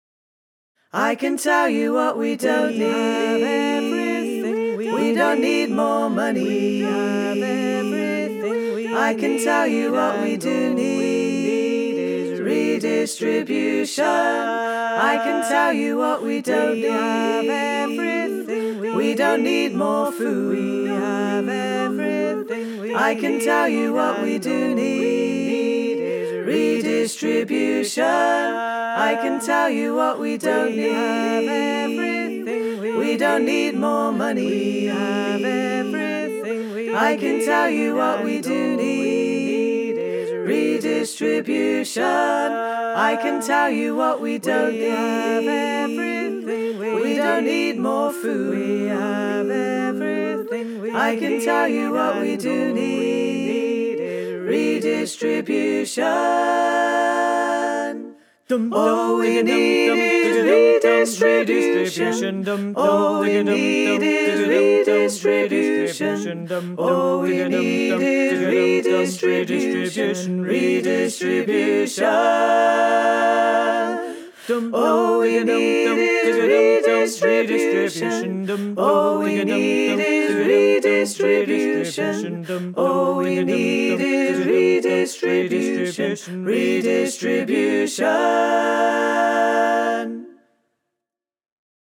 redistribution_learning-tracks_all-parts.wav